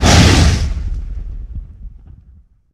punch1.ogg